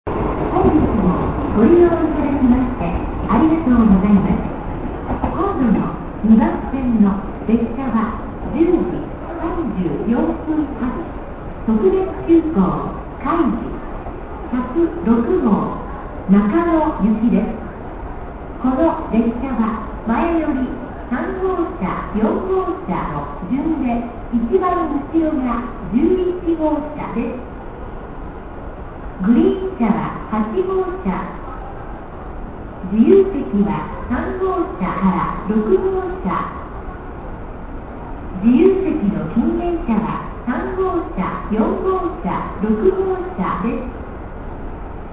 予告放送「特別急行かいじ106号」中野行き